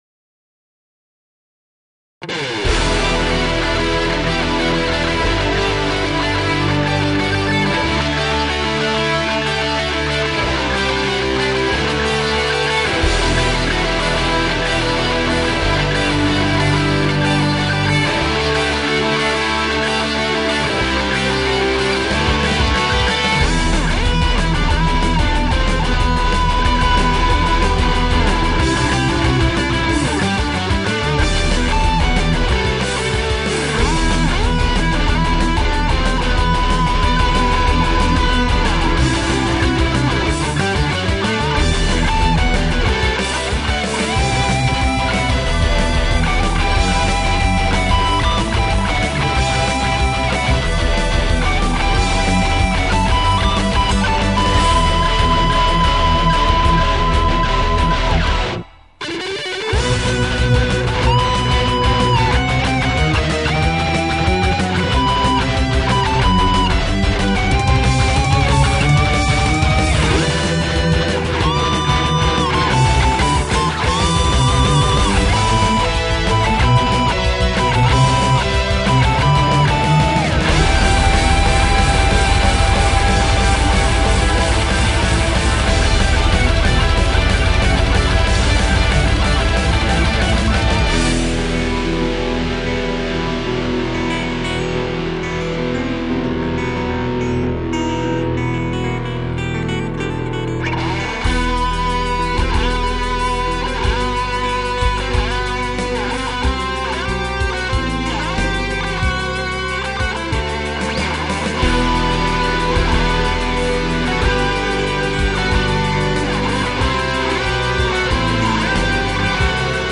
guitar instrumental
このページの曲はすべてコピー（またはｶｳﾞｧｰ）です。